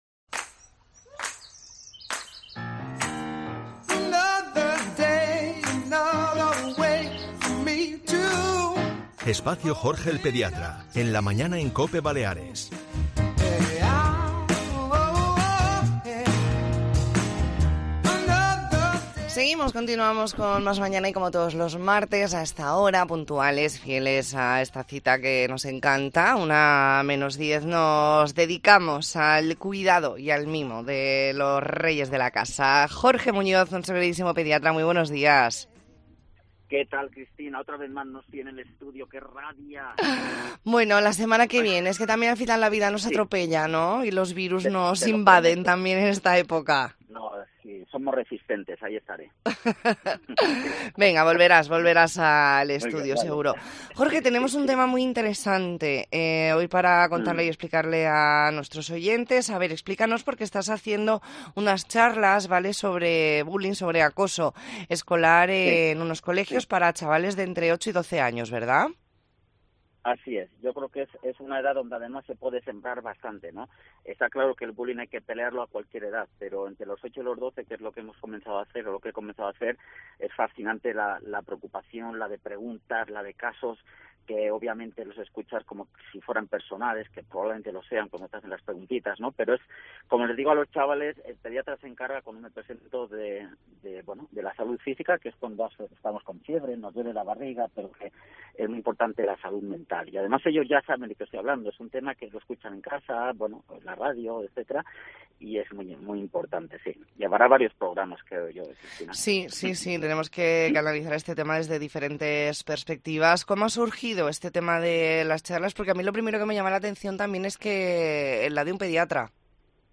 Hoy nos explica herramientas para que un menor que sufra acoso escolar pueda salir de esta situación. Entrevista en La Mañana en COPE Más Mallorca, martes 28 de noviembre de 2023.